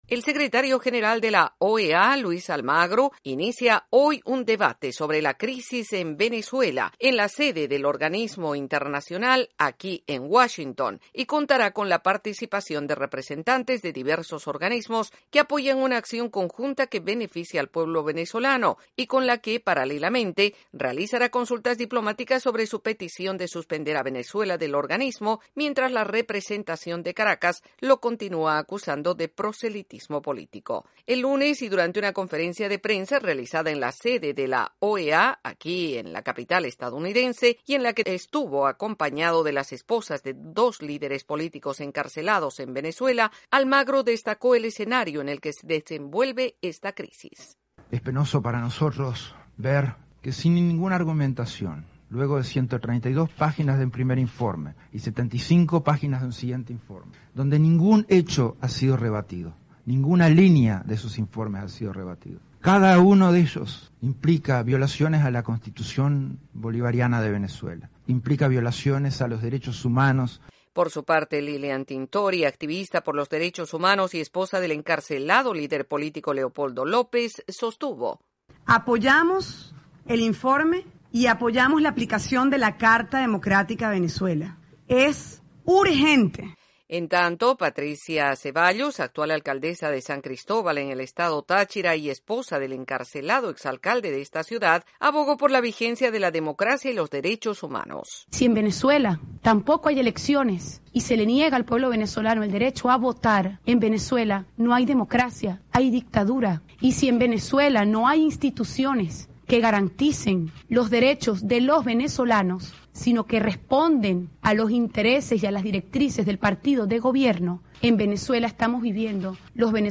El secretario general de la OEA inicia hoy un debate sobre la crisis en Venezuela luego de reiterar los detalles de su informe y recibir apoyo de las esposas de líderes políticos encarcelados en la nación sudamericana. Desde la Voz de América en Washington DC informa